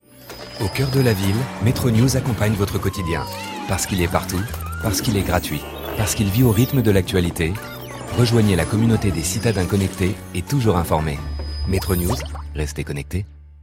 Voix off homme pro